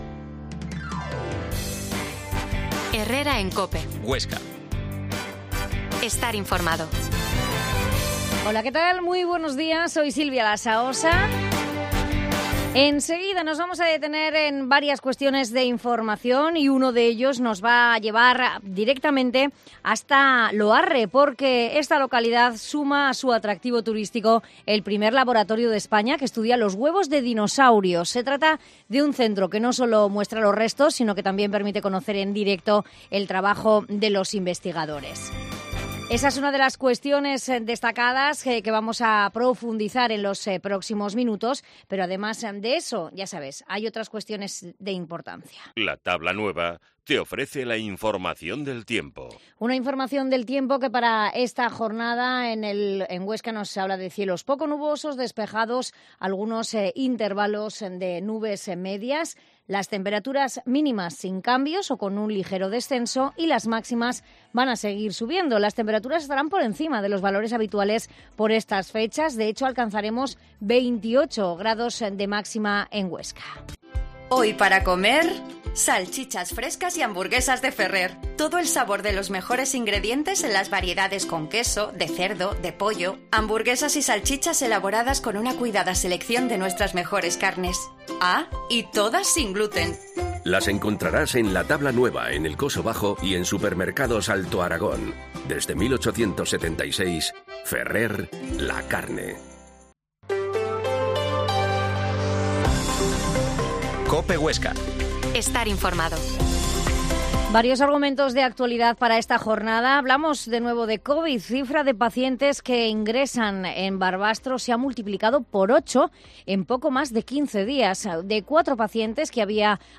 Herrera en COPE Huesca 12.50h Entrevista al consejero de turismo de la Hoya de Huesca Roberto Orós